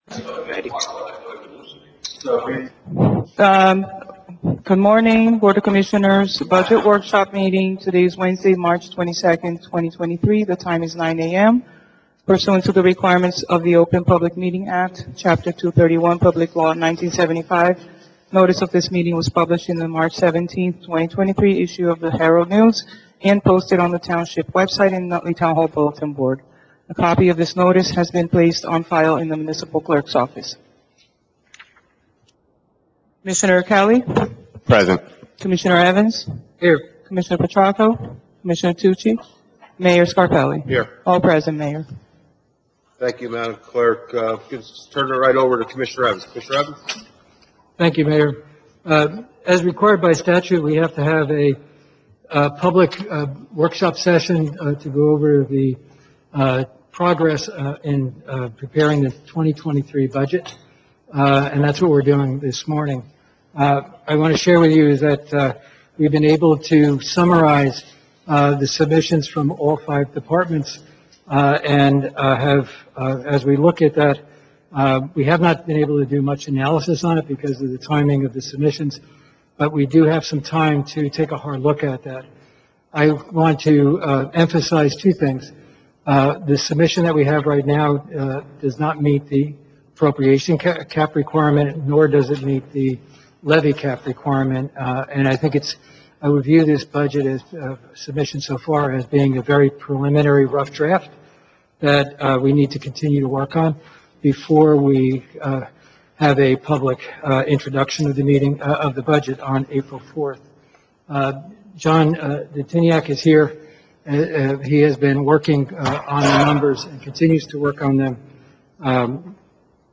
Town Hall - 3rd Floor Commission Chambers 1 Kennedy DriveNutley, NJ 07110 Click for Directions
SUNSHINE NOTICE BOARD OF COMMISSIONERS 2023 BUDGET WORKSHOP MEETING